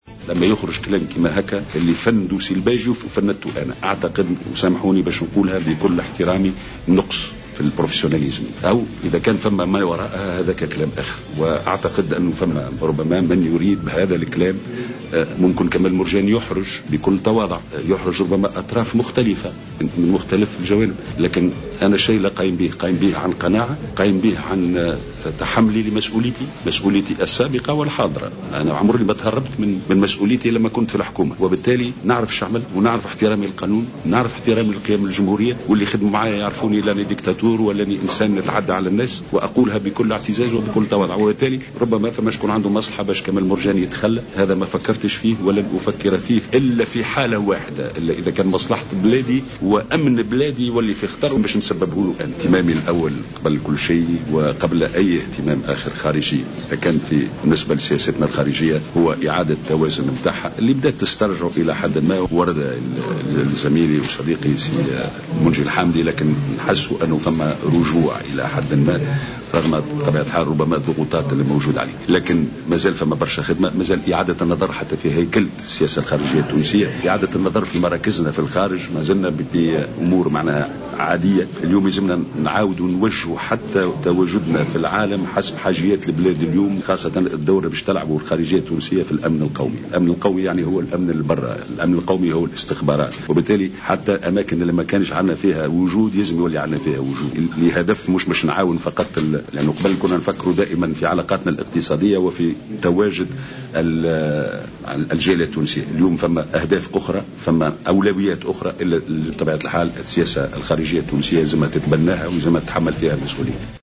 أشرف اليوم الأحد المرشح الرئاسي كمال مرجان على اجتماع شعبي بأحد نزل مدينة سوسة في نطاق حملته الانتخابية.
وعبر مرجان في تصريح لجوهرة أف أم عن استغرابه لنشر إشاعة تراجعه عن الترشح للانتخابات الرئاسية لفائدة الباجي قائد السبسي، معتبرا أن ترشحه قد أحرج بعض الأطراف ما دفعها إلى إخراج هذا الخبر.